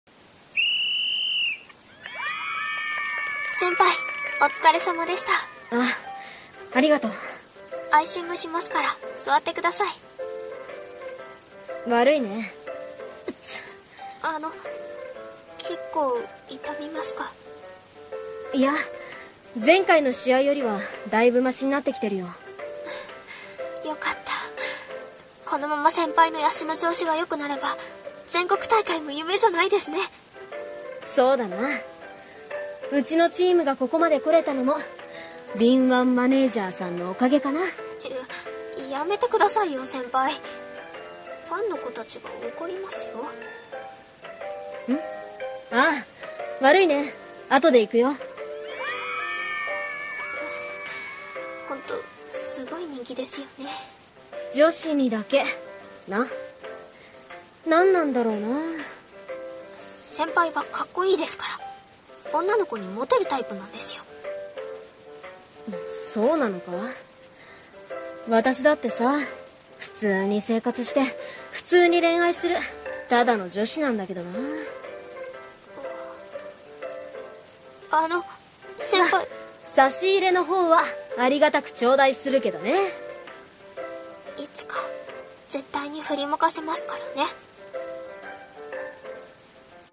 【コラボ声劇】恋の逆転シュート